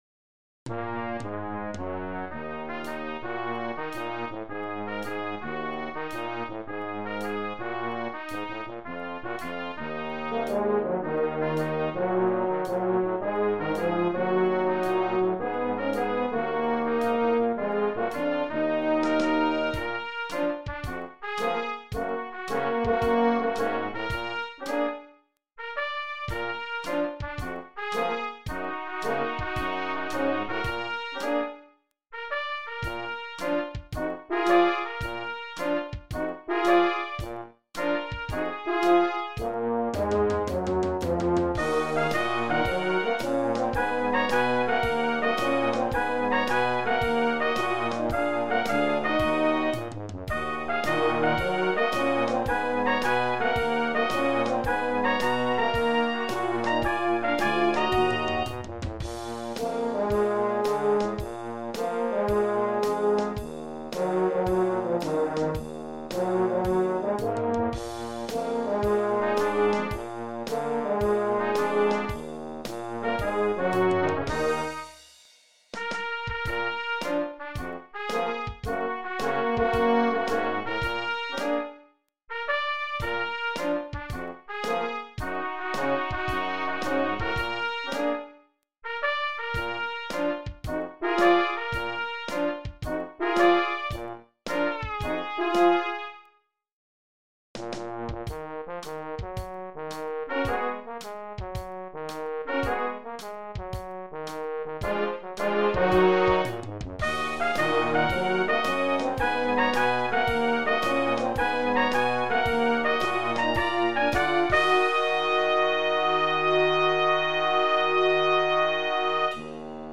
Arranged for Brass Quartet and Drums (optional).